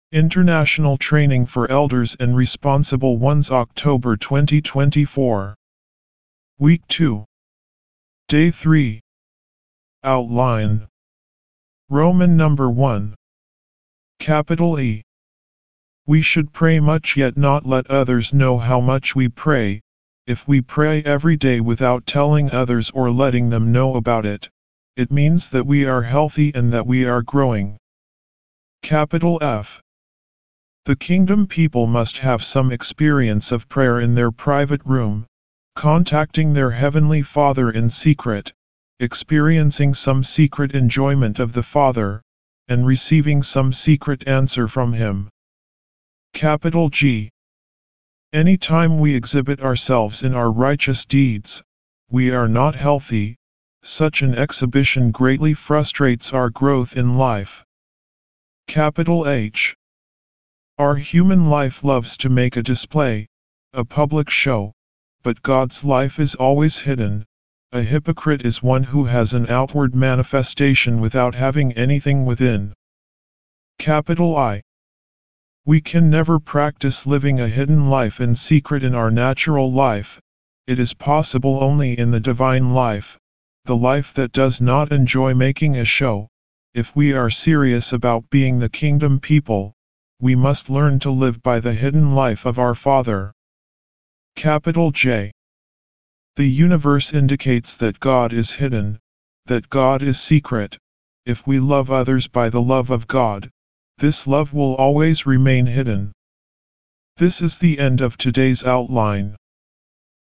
D3 English Rcite：